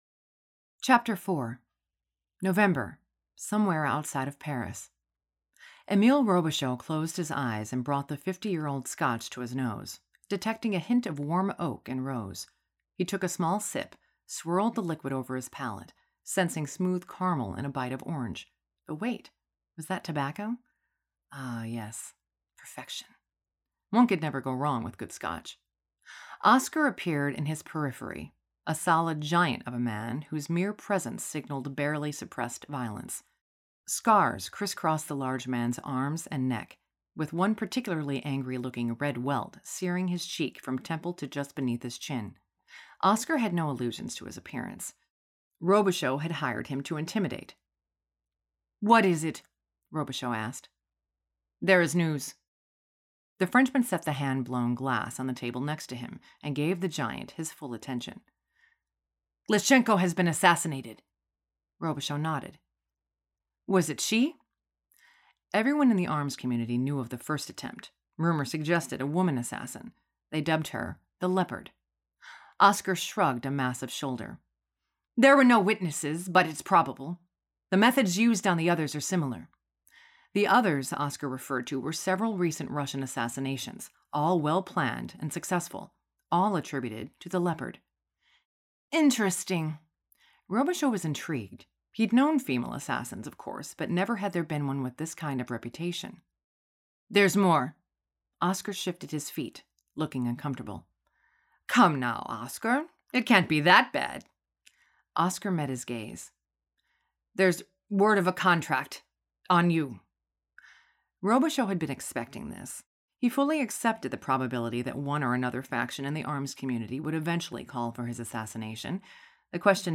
• Audiobook
A Killing Truth Chap 4 Retail Sample.mp3